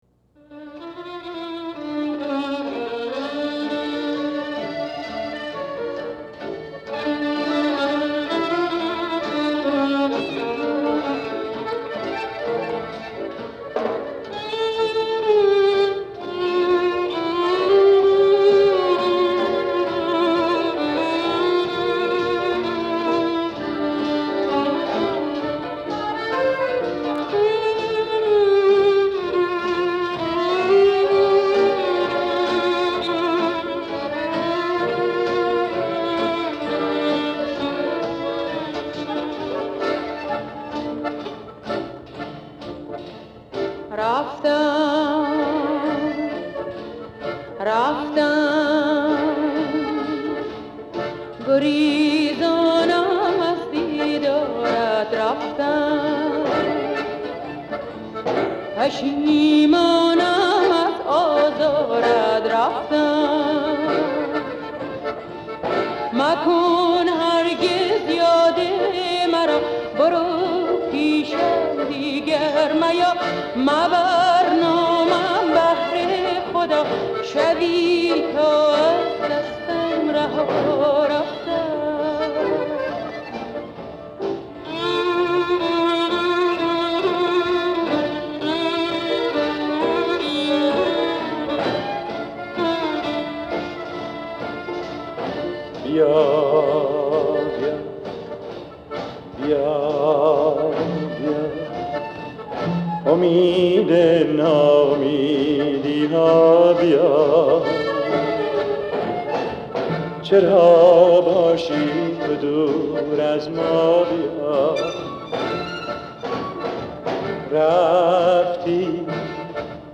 در مایه اصفهان